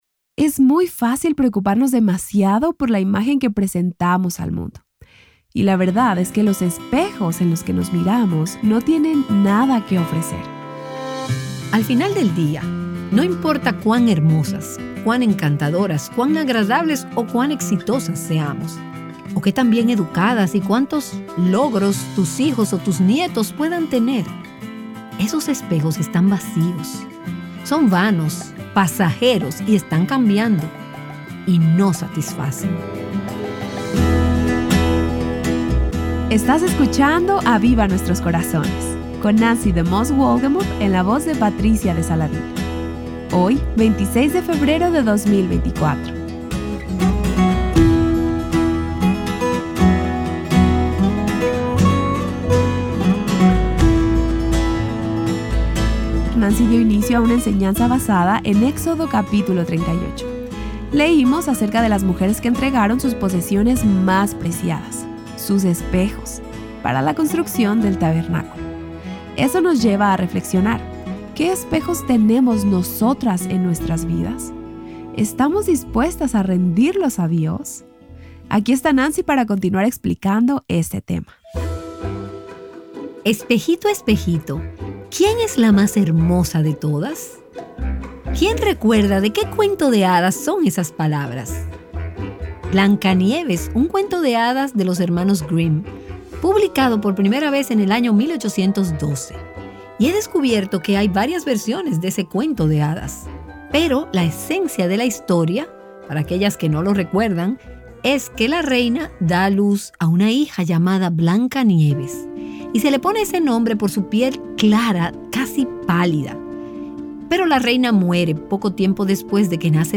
No te pierdas la enseñanza de hoy.